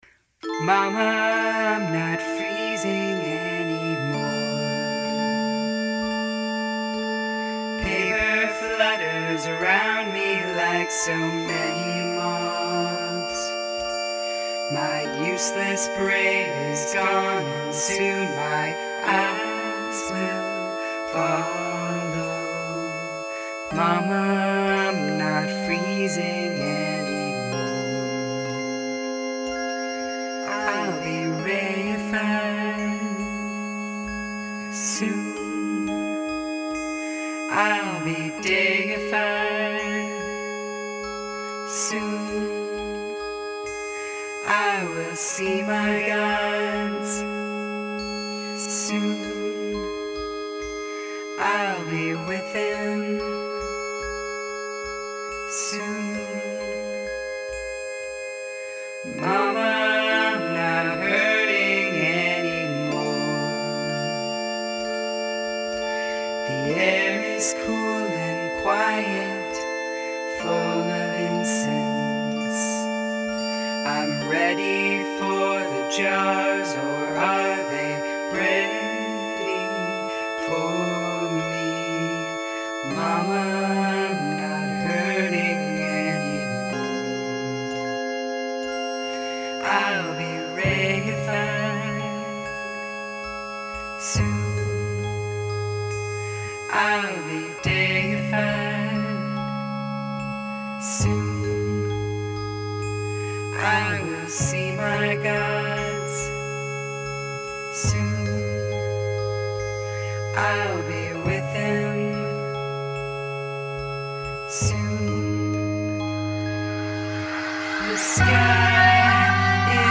vocals, Q-Chord